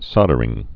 (sŏdər-ĭng)